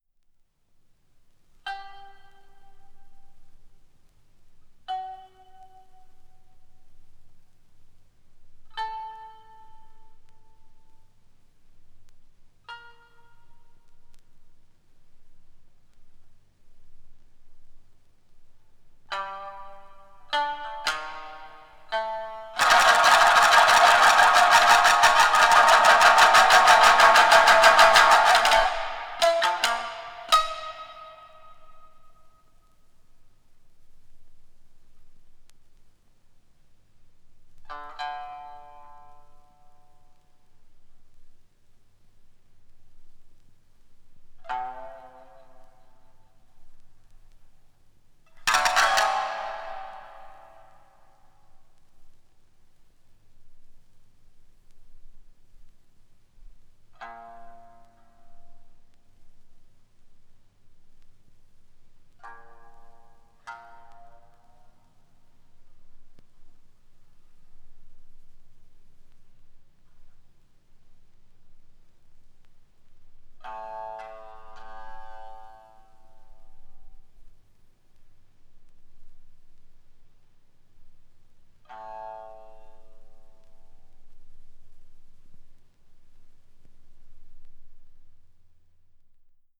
三絃